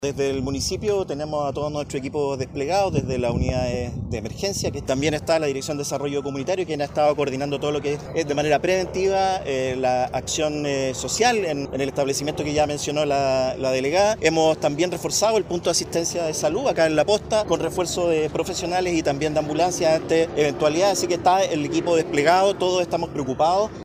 A su vez, el alcalde subrogante de Valparaíso, Alejandro Escobar, dio cuenta que el municipio ha desplegado recursos y equipos.